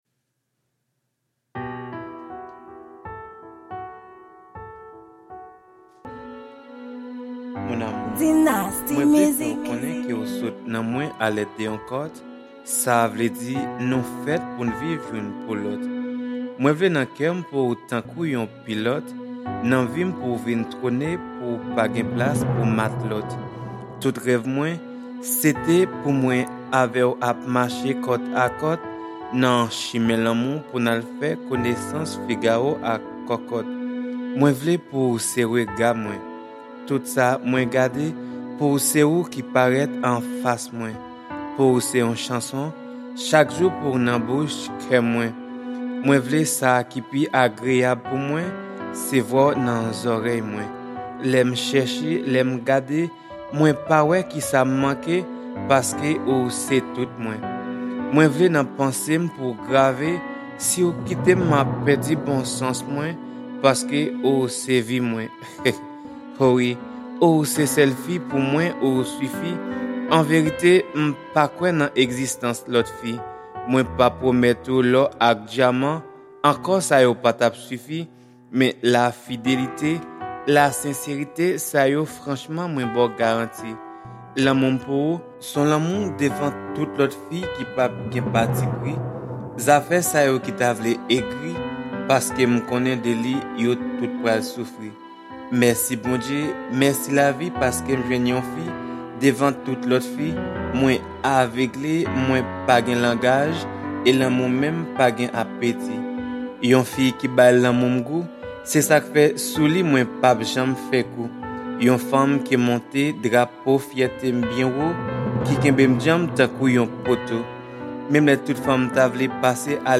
Genre: Slam